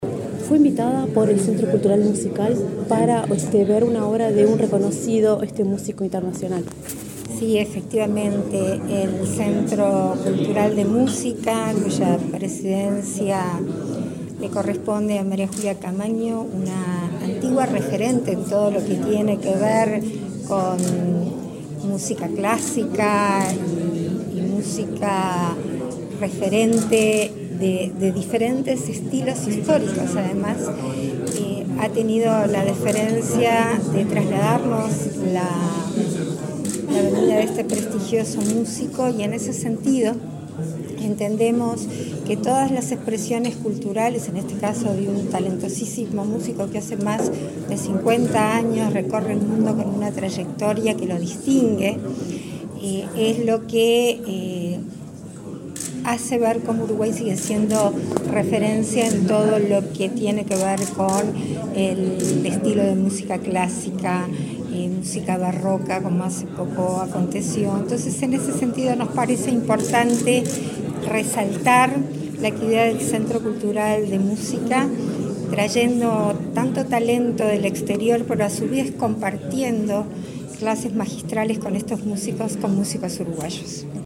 Declaraciones de la presidenta en ejercicio, Beatriz Argimón, en el aeropuerto de Carrasco
Este domingo 17, la presidenta de la República en ejercicio, Beatriz Argimón, dialogó con Comunicación Presidencial en el aeropuerto de Carrasco,